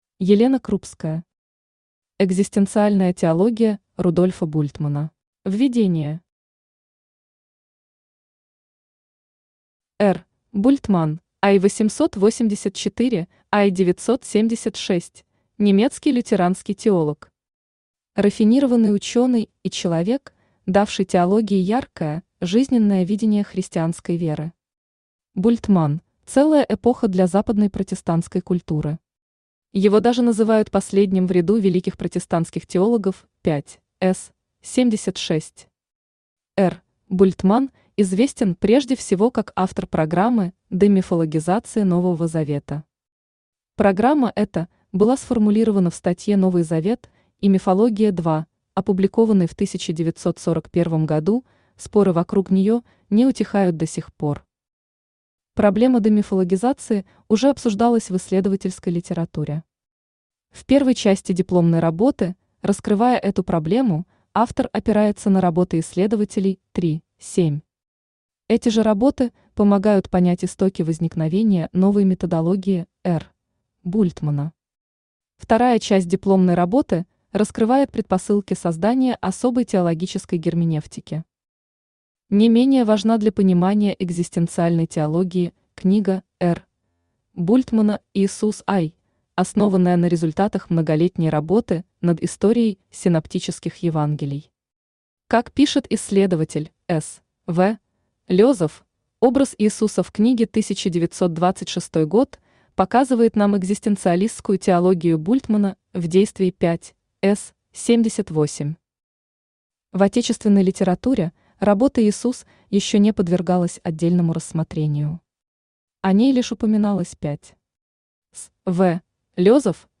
Аудиокнига Экзистенциальная теология Рудольфа Бультмана | Библиотека аудиокниг
Читает аудиокнигу Авточтец ЛитРес